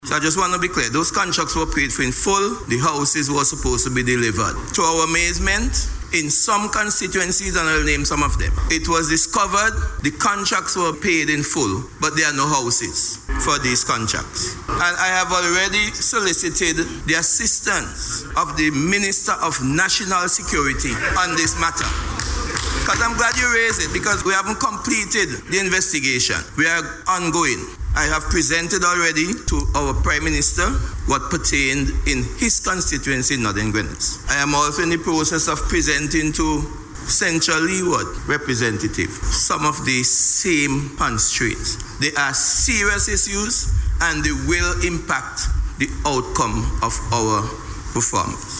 Minister John outlined the constraints, as he responded to a question in Parliament last week.